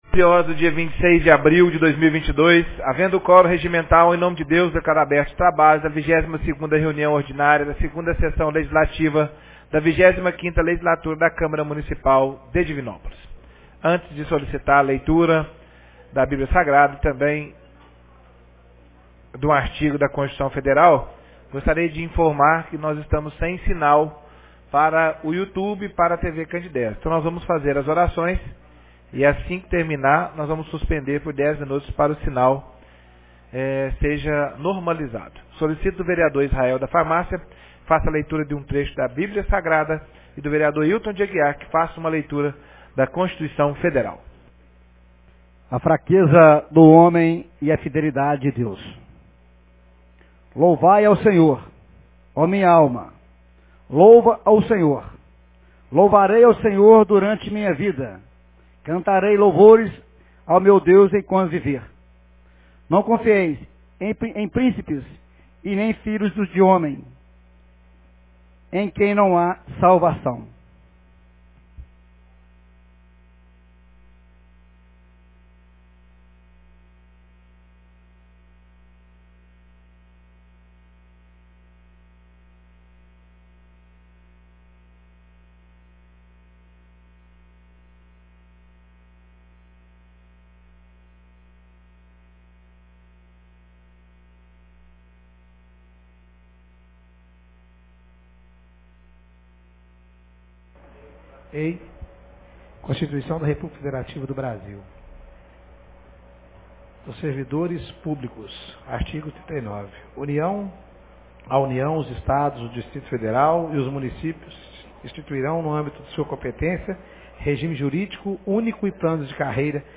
22ª Reunião Ordinária 26 de abril de 2022